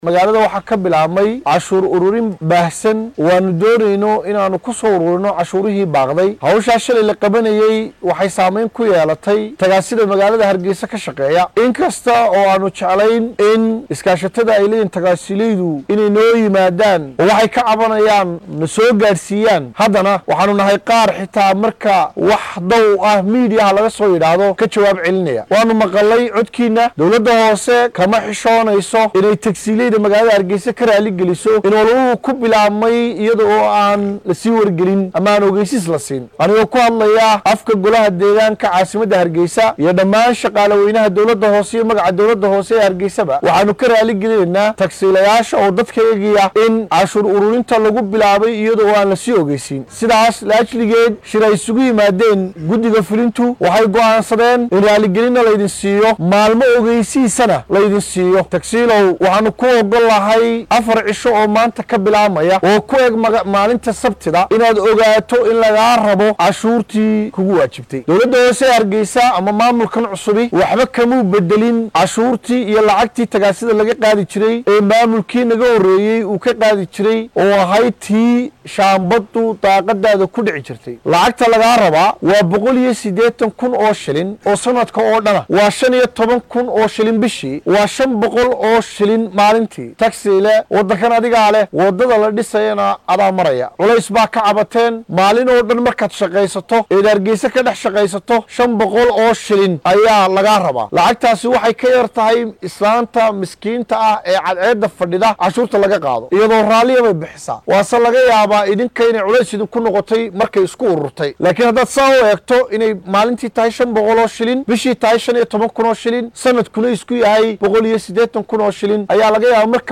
Duqa Caasimadda Soomaaliland ee Hargeysa C/kariim Axmad Mooge oo maanta warbaahinta kula hadlay magaaladaasi ayaa Raaligelin Siiyay Gaadiidlayda Tagaasida ee magaaladaasi ka howlgala oo ay waaxda canshuuraha magaalada Hargeysa ay howlgallo canshuuro uruurin ka sameysay halkaasi shalay. Waxaa uu sheegay in tagaasilayda laga raaligalinayo howlgalkaasi degdega ah. Waxaa uuna siiyay muddo 4 cisho ah oo ay isaga bixinayaan lacagta canshuurta ah.